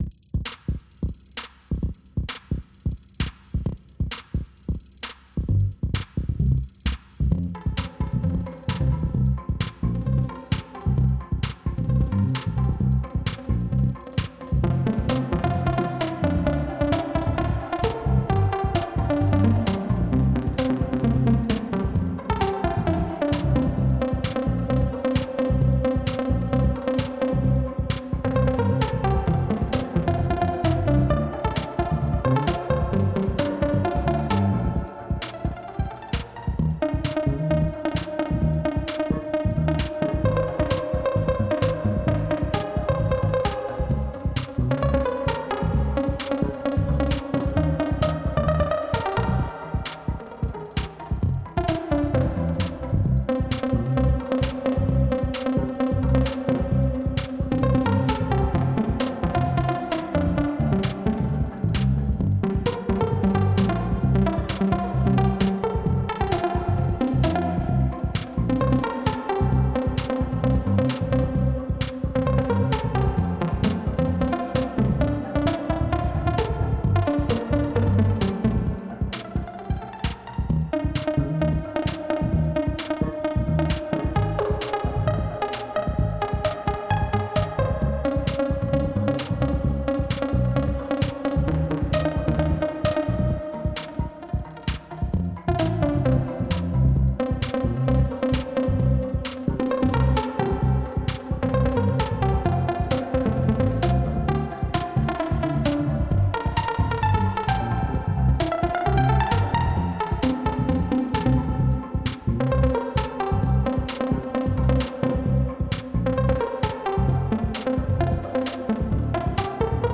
Electric Bass, Drums, Synths.
Genre: Hip-Hop